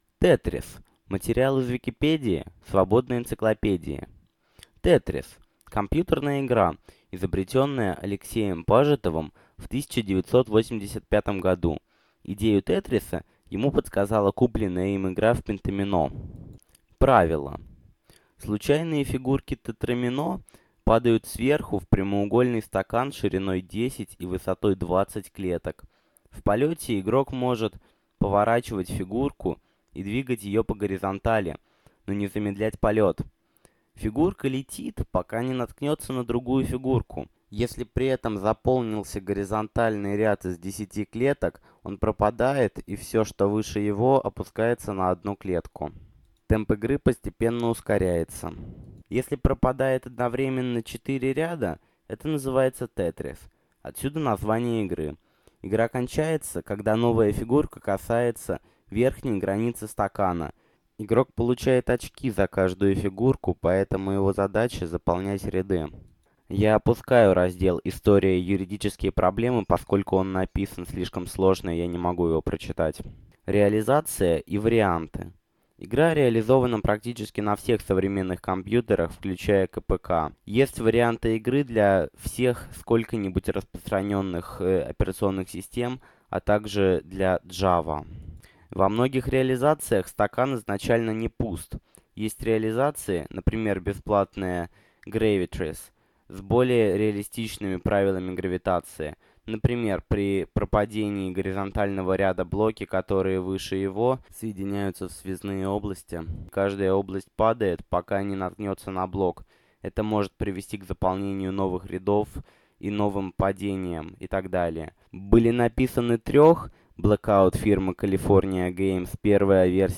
Длительность: 4 м 32 с, битрейт: 94 Кбит/с, размер файла: 3,06 МБ) Это — аудиоверсия статьи « Тетрис ».
Акцент: Московский Пол: Мужской